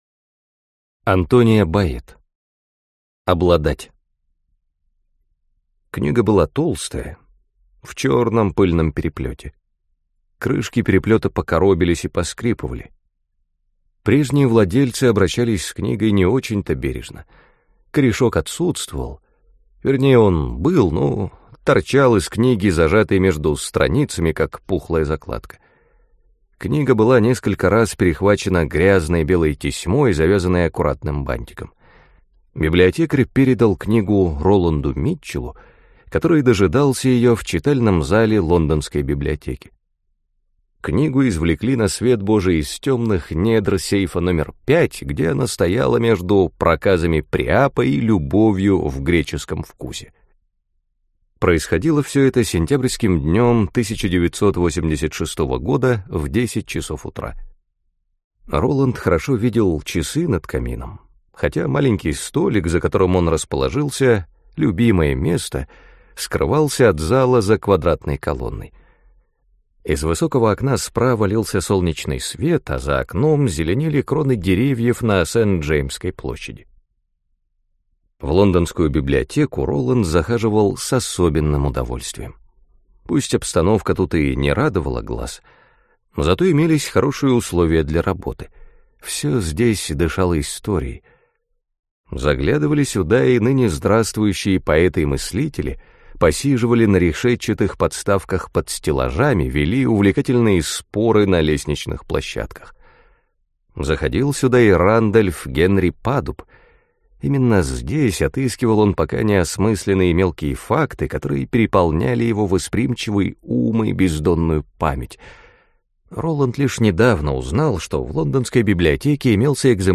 Аудиокнига Обладать | Библиотека аудиокниг